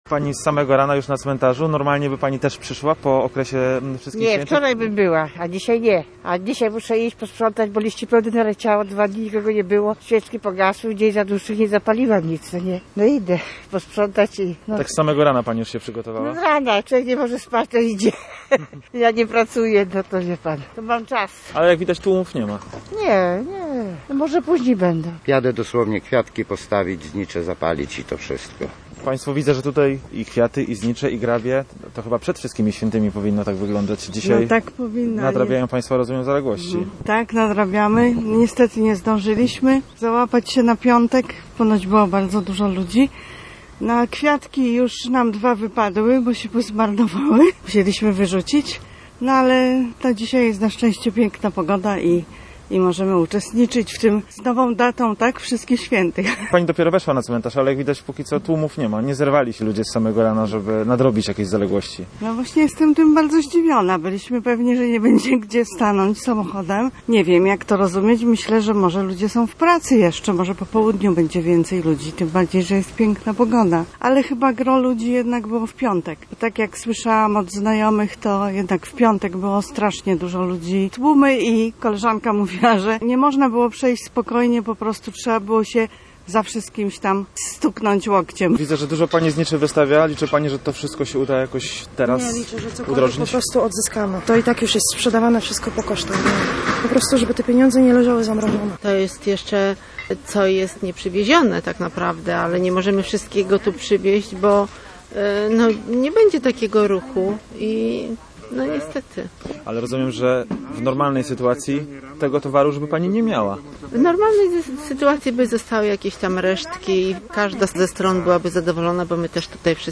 Mieszkańcy, którzy już po godzinie 7:00 przyszli na Stary Cmentarz w Słupsku, mówili reporterowi Radia Gdańsk, że chcą nadrobić zaległości z ostatnich trzech dni.